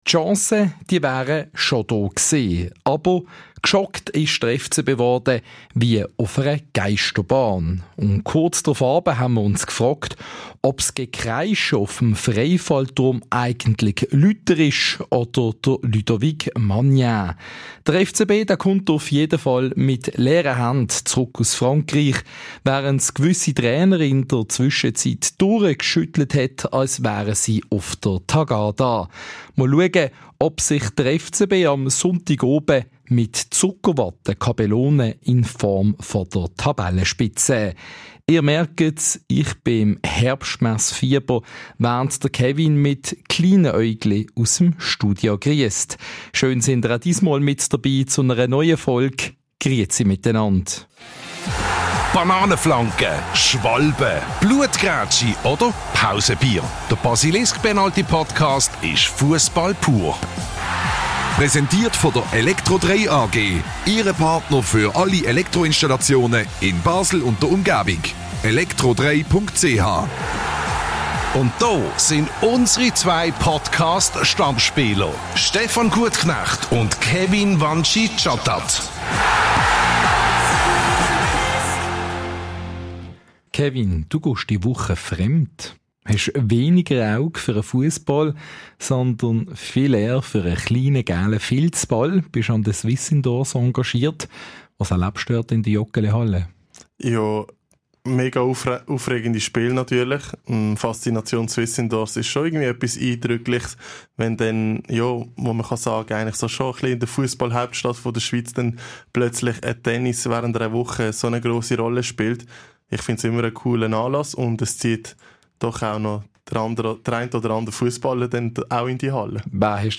Es wäre mehr möglich gewesen, ist sich unser Podcast-Duo einig.